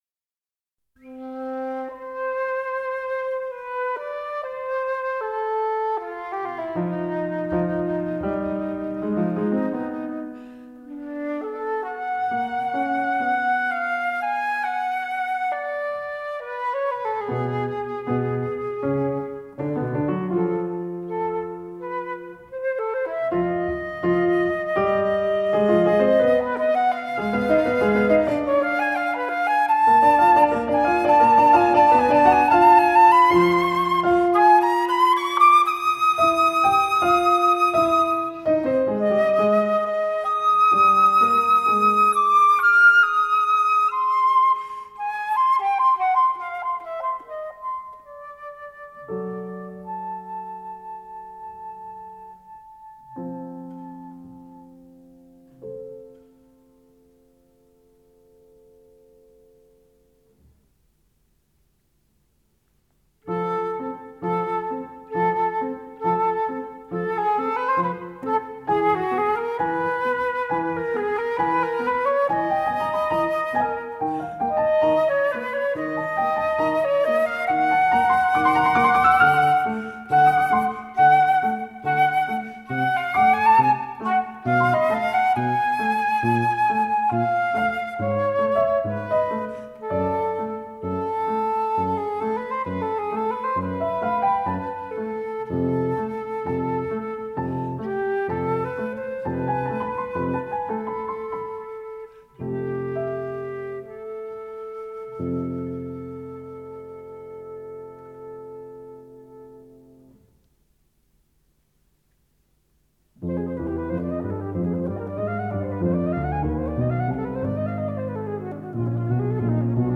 In seven parts.